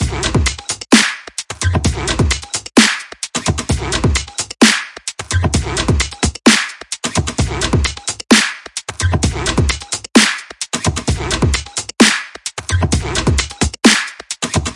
他们的女人" 鼓04
Tag: 寒意 旅行 电子 舞蹈 looppack 样品 毛刺 节奏 节拍 低音 鼓实验 器乐